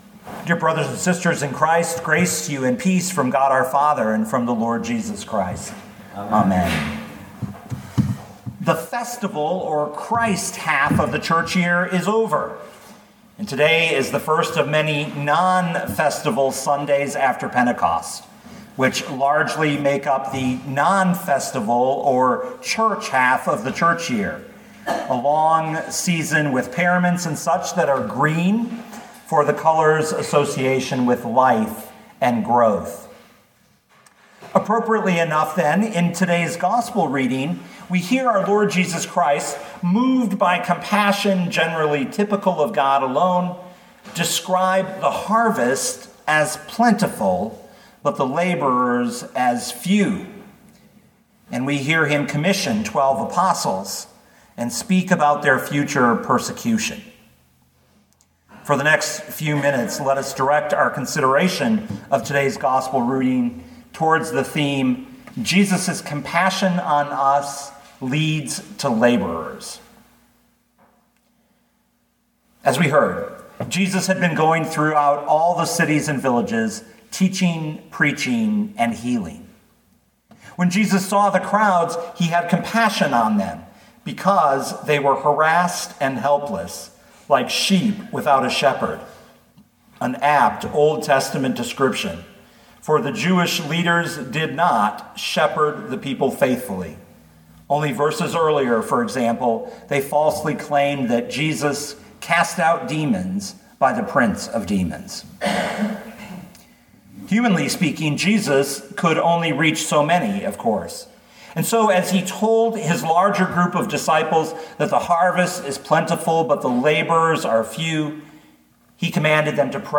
2020 Matthew 9:35-10:20 Listen to the sermon with the player below, or, download the audio.